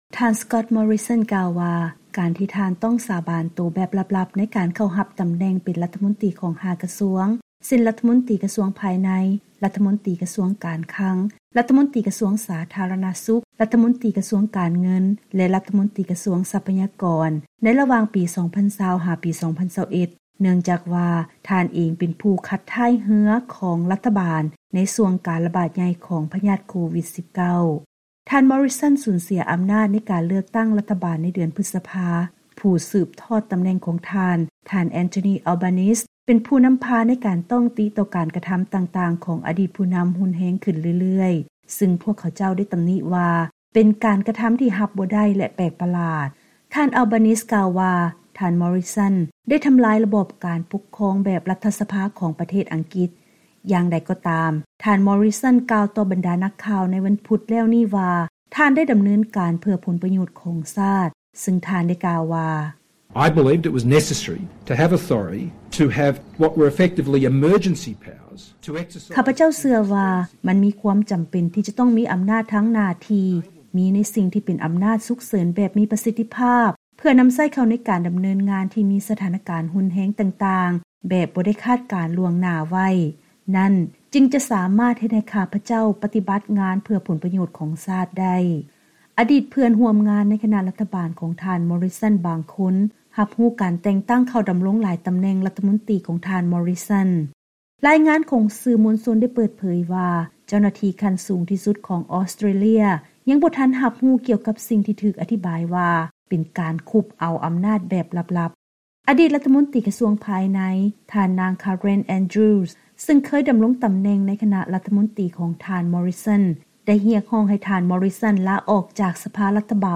ເຊີນຮັບຟັງລາຍງານກ່ຽວກັບ ຄວາມພະຍາຍາມຂອງທ່ານມໍຣິສັນ ໃນການອະທິບາຍກ່ຽວກັບເຫດຜົນການແຕ່ງຕັ້ງຕົນເອງຂຶ້ນເປັນລັດຖະມົນຕີຕ່າງໆ